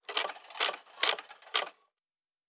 Metal Tools 09.wav